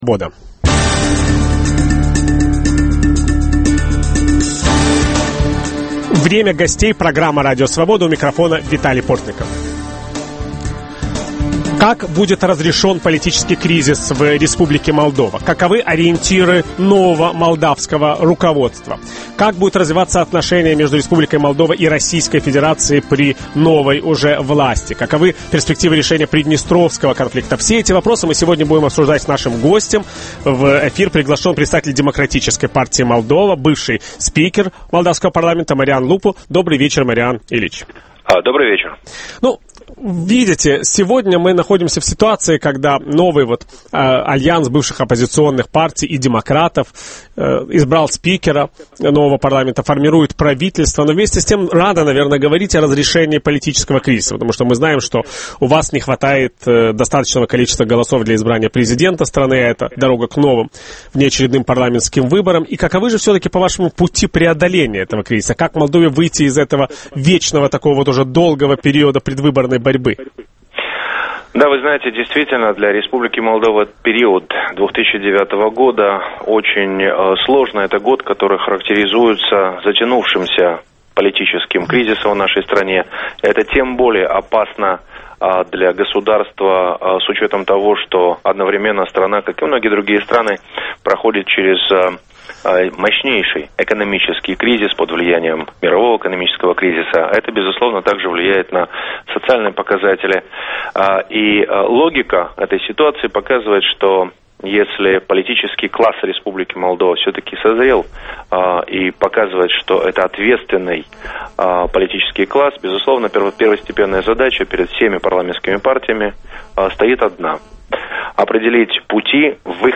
О том, как будет развиваться политическая ситуация в Молдавии ведущий беседует с лидером Демократической партии, бывшим спикером парламента страны Марианом Лупу.